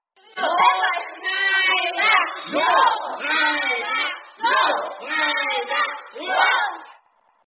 Hiệu ứng âm thanh Tiếng hô 123 Dzô, 23 uống… (giọng nữ) - Tải Mp3